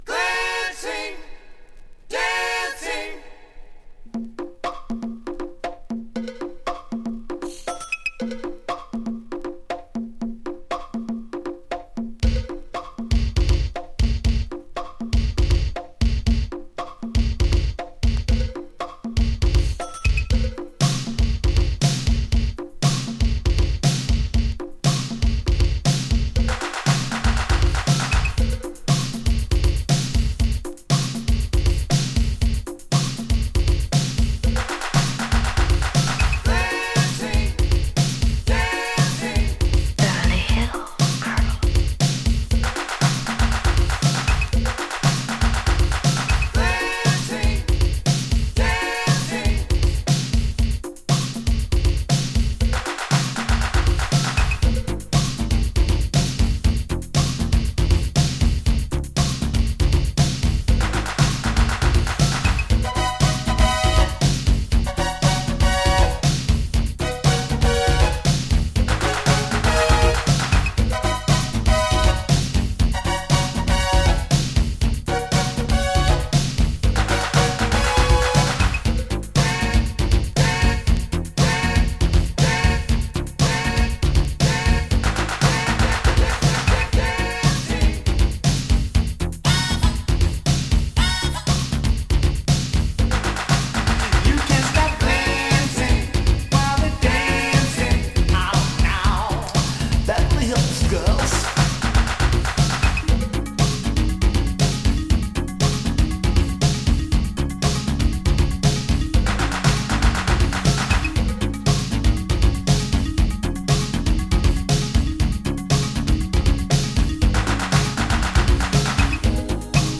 Indie Electro Boogie！
【DISCO】【BOOGIE】